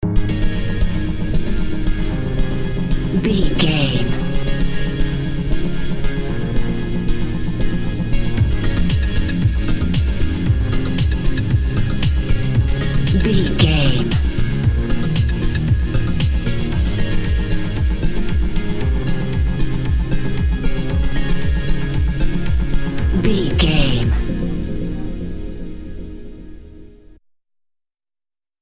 Aeolian/Minor
ethereal
dreamy
cheerful/happy
groovy
synthesiser
drum machine
house
techno
trance
synth bass
upbeat